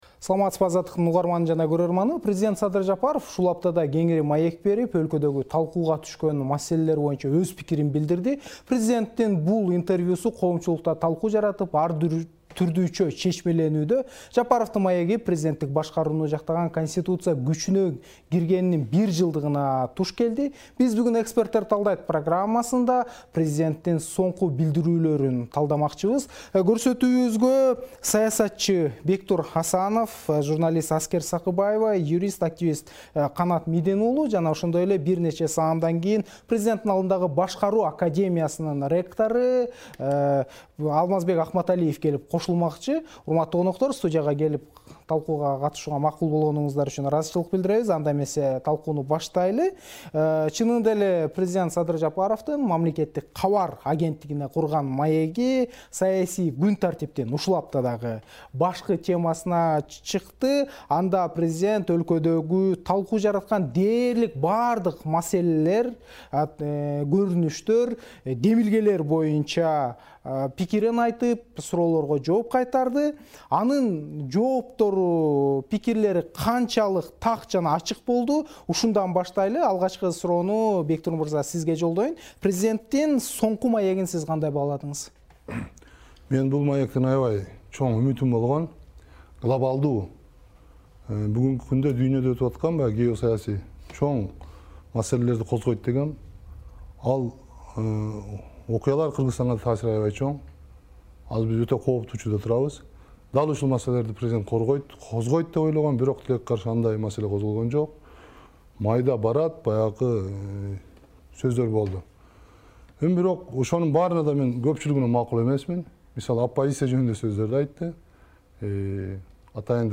"Эксперттер талдайт" программасында президенттин соңкү билдирүүлөрүн талдайбыз.